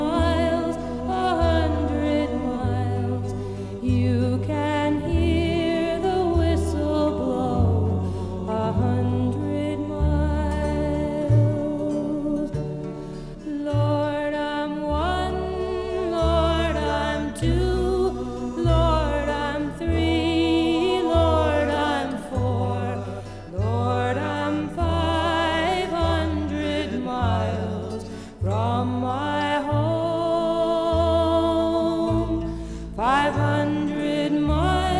Жанр: Рок / Фолк-рок